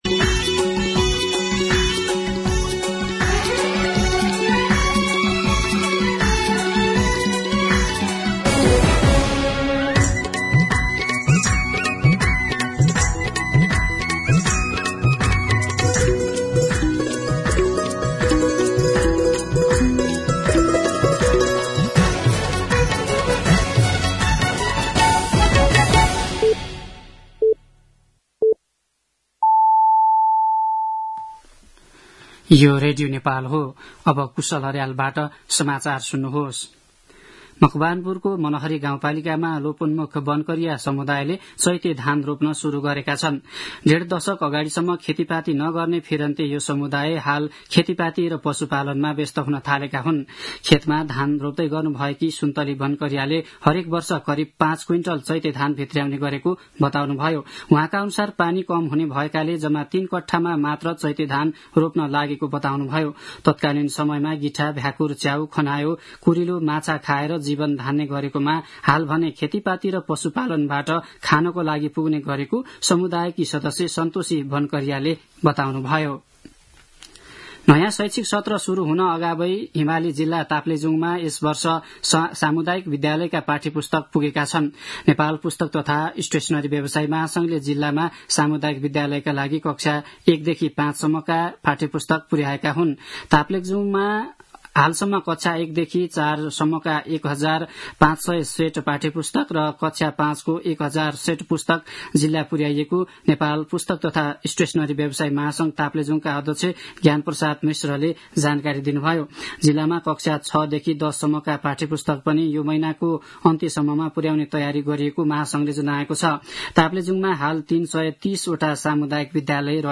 दिउँसो ४ बजेको नेपाली समाचार : २७ फागुन , २०८१
4pm-News-11-26.mp3